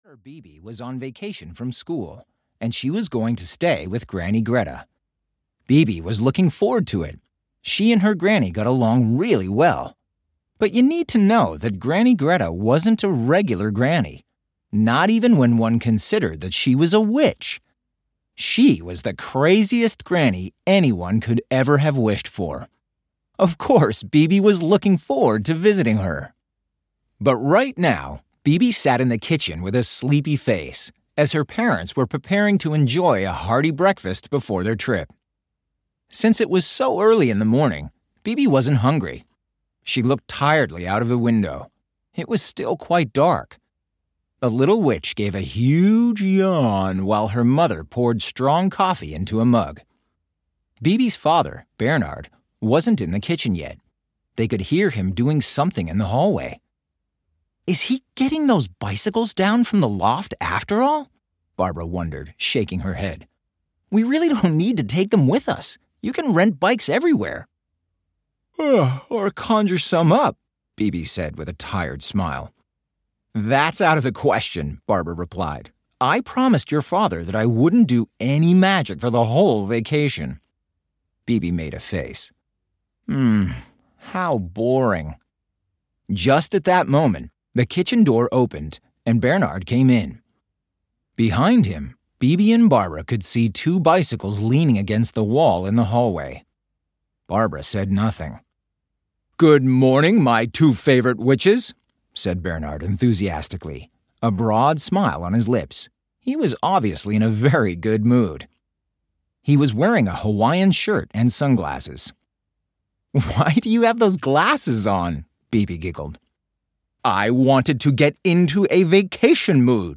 Audiobook Bibi Blocksberg - In the Valley of the Wild Witches. Follow Bibi Blocksberg as she makes new friends, joins a witch party, and sees a dragon!
Ukázka z knihy
As one of the most successful children's audio drama series, Bibi Blocksberg has been awarded several gold and platinum records in Germany.©2023 KIDDINX Studios, Licensed by Kiddinx Media GmbH, Berlin